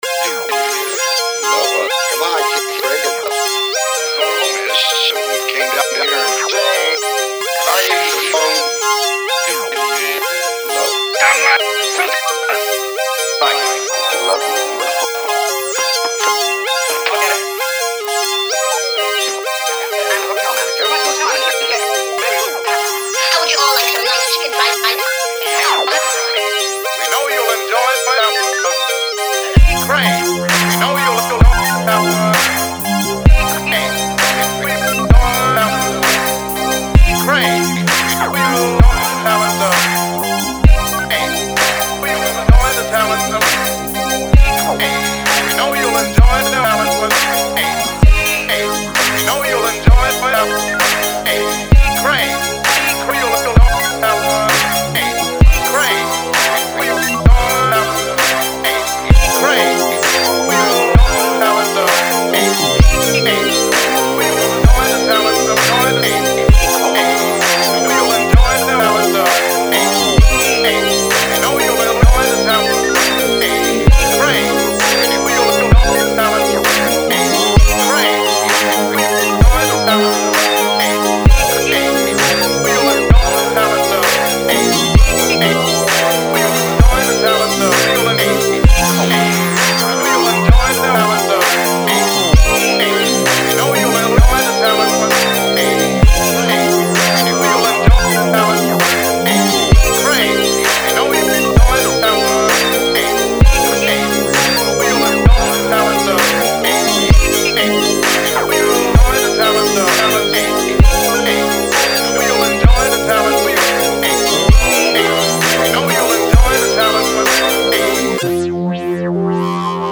ELECTRONICA MUSIC ; CINEMATIC MUSIC